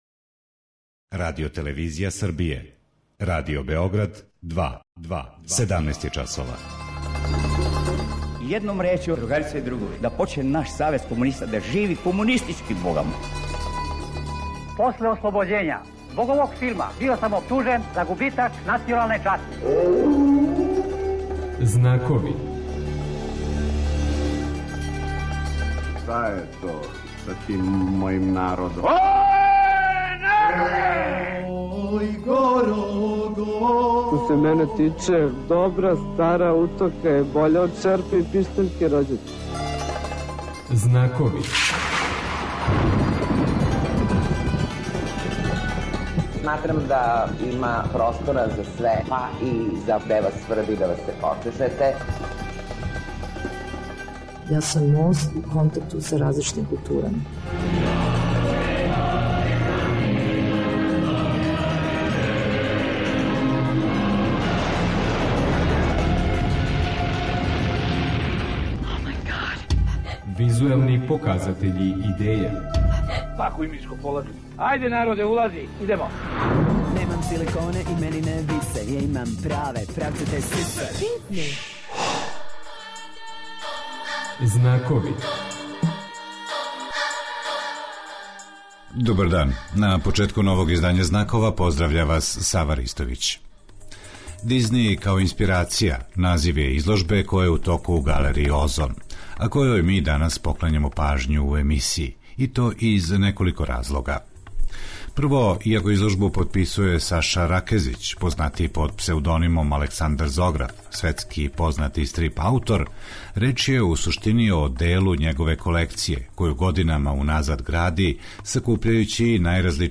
Поводом изложбе 'Дизни као инспирација' у галерији 'О3оnе', на којој је представио део своје колекције, гост 'Знакова' ће бити Саша Ракезић ака Александар Зограф.